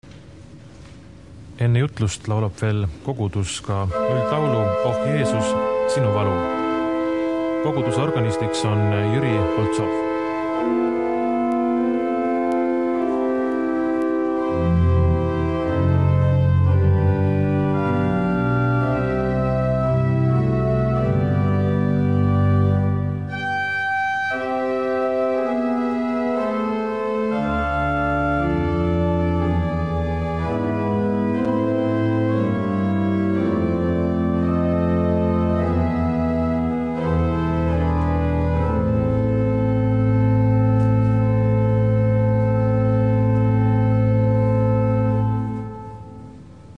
Urkujen
principal-sointia, pehmeitä